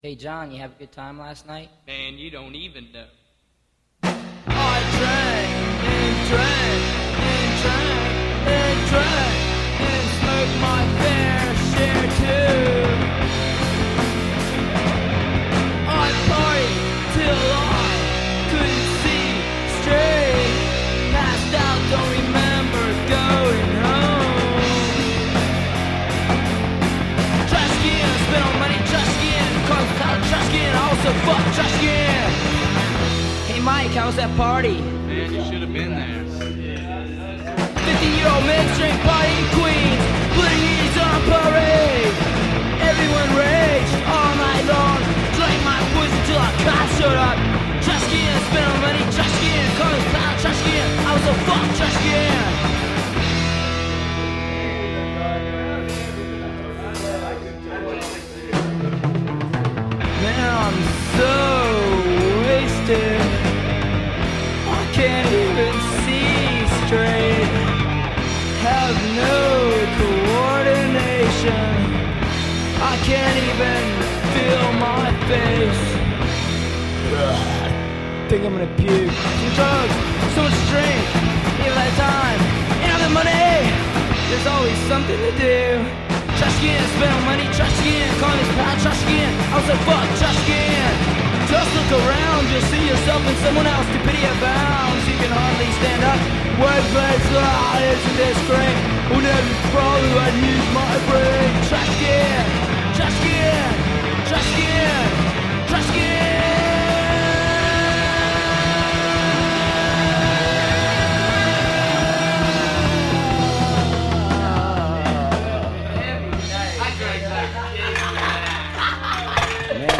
vocals
guitar
bass
drums